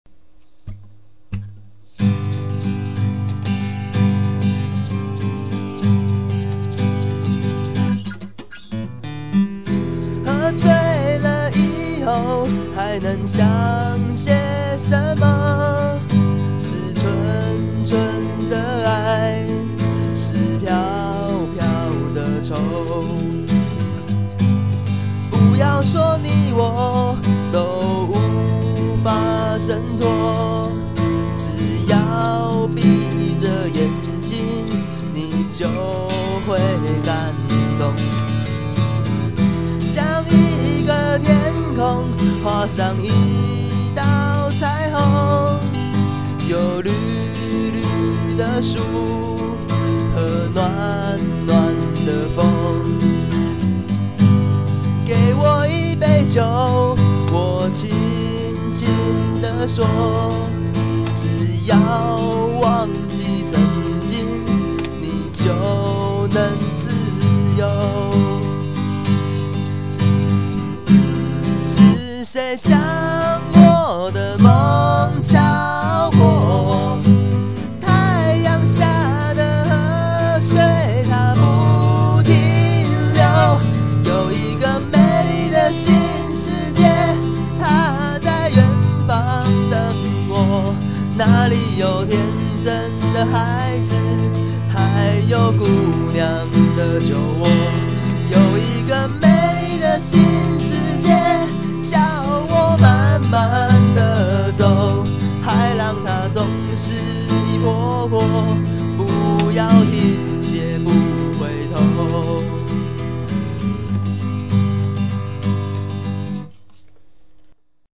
吉他+沙鼓 這是我自己錄的 如果不能po請告知一下 我會自D Play